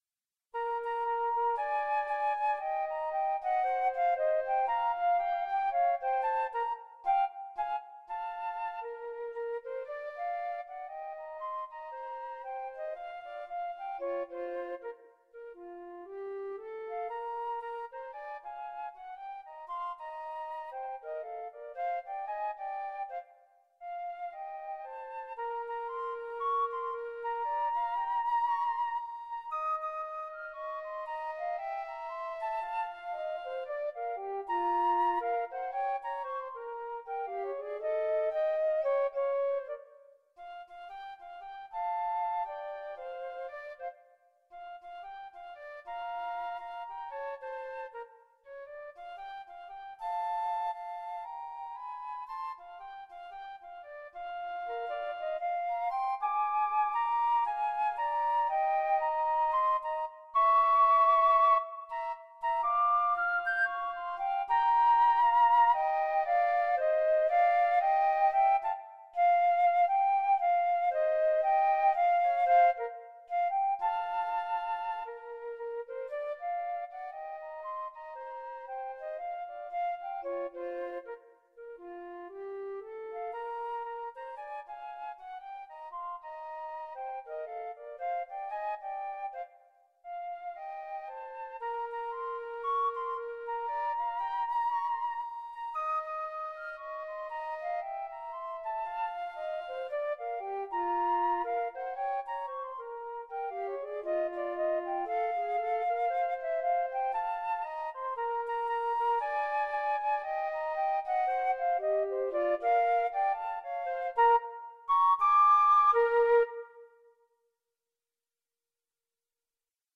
An original swing number
Jazz and Blues